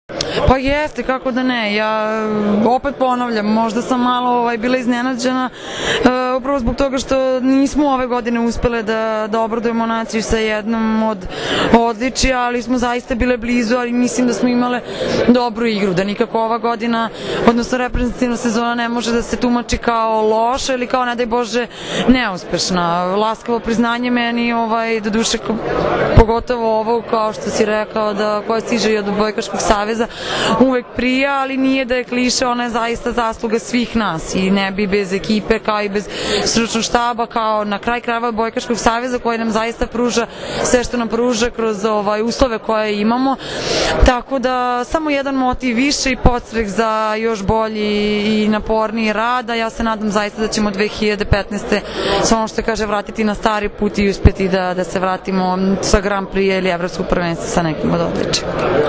Odbojkaški savez Srbije je danas u beogradskom hotelu “Metropol Palas” organizovao Novogdišnji koktel “Naša strana mreže”, na kojem su dodeljeni Trofeji “Odbojka spaja”, “Budućnost pripada njima”, Trofeji za najbolju odbojkašicu i odbojkaša i najbolju odbojkašicu i odbojkaša na pesku, kao i prvi put “specijalna plaketa OSS”.
IZJAVA MAJE OGNJENOVIĆ